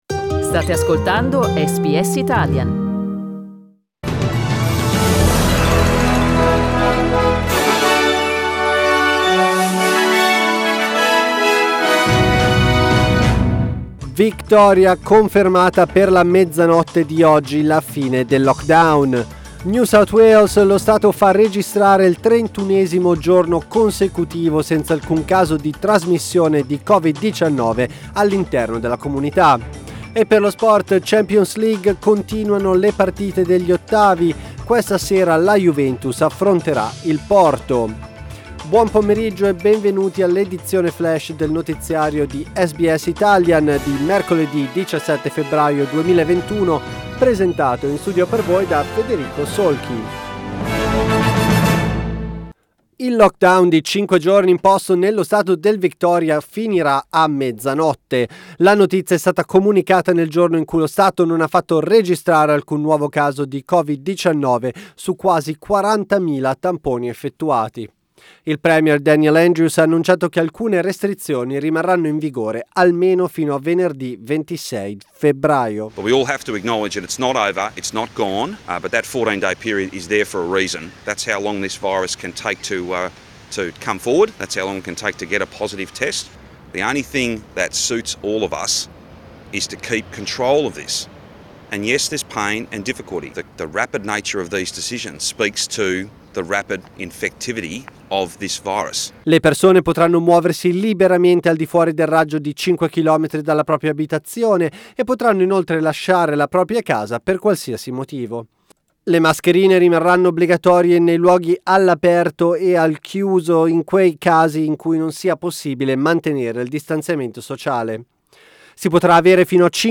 Our news update in Italian